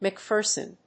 /mʌˈkfɝsʌn(米国英語), mʌˈkfɜ:sʌn(英国英語)/